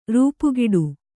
♪ rūpugiḍu